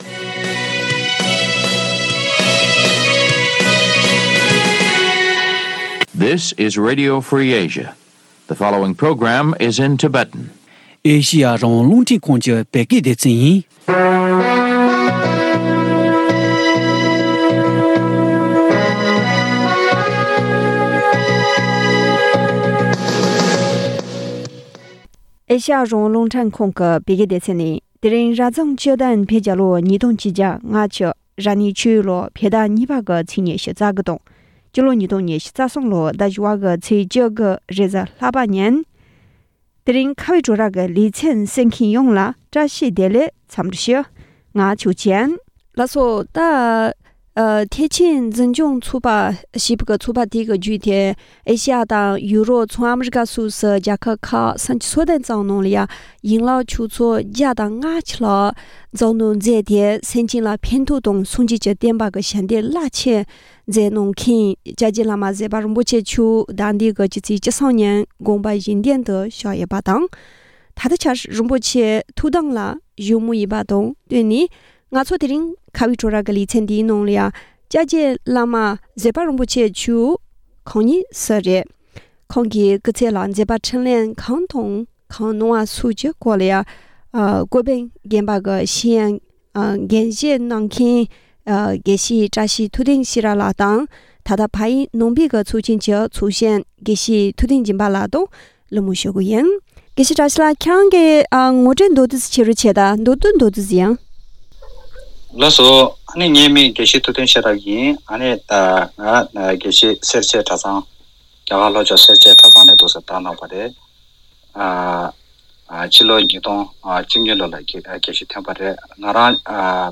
བཀའ་འདྲི་ཞུས་པ།